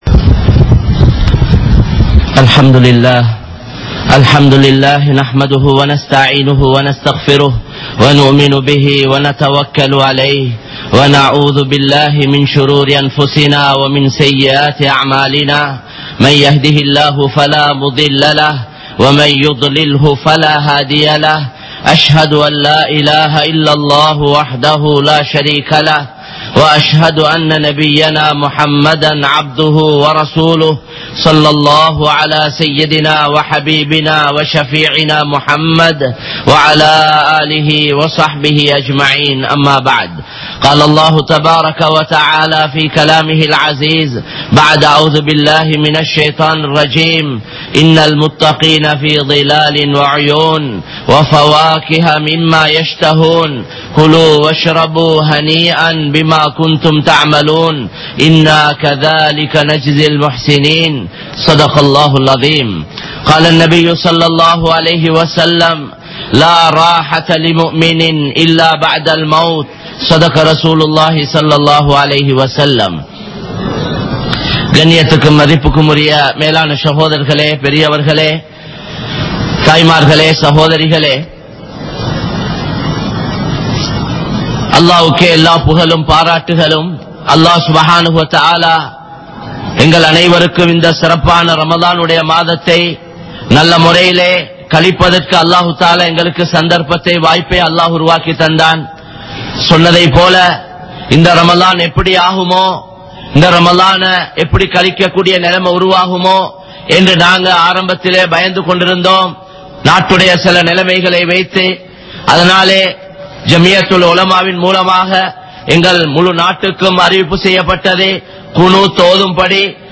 Allah`vin Pidi | Audio Bayans | All Ceylon Muslim Youth Community | Addalaichenai